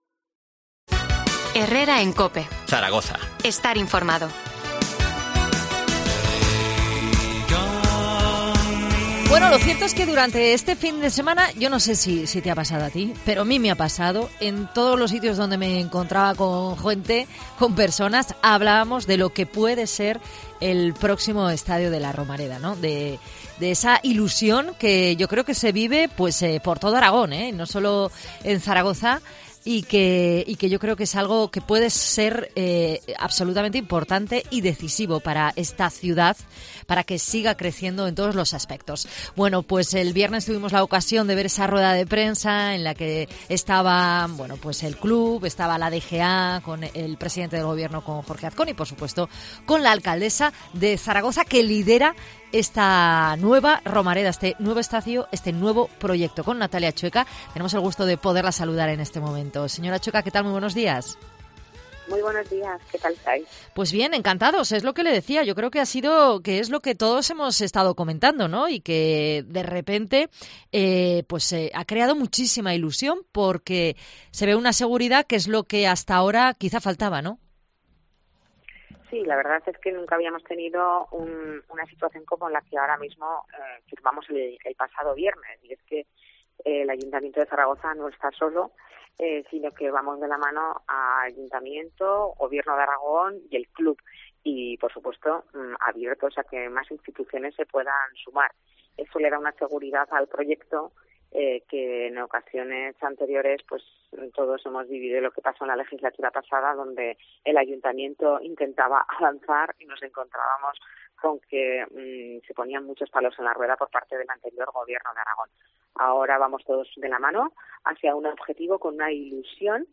AUDIO: Entrevista en COPE a la alcaldesa de Zaragoza, Natalia Chueca, sobre la nueva Romareda.